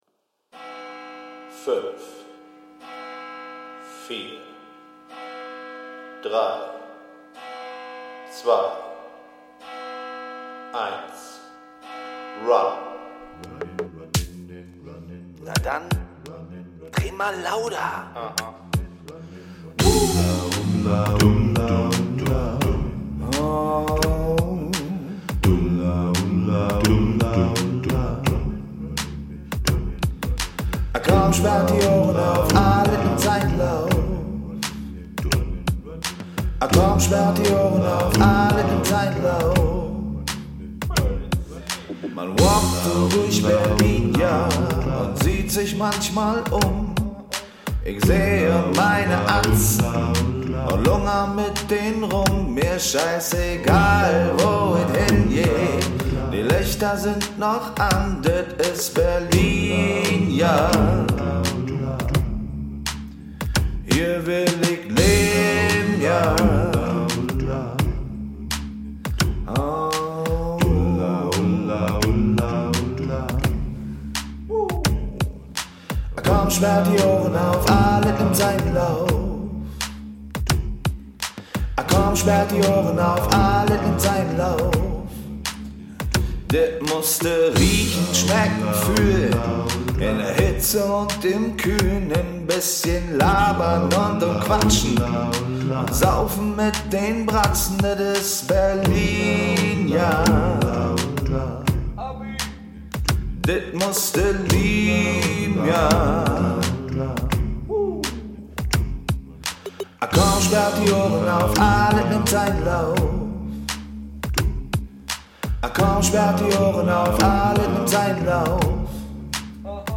Der Song zum Podcast